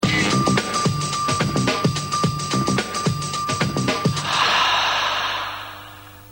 Jingle d'avant programme : Sport ( 1991 - 00:06 - MP3 )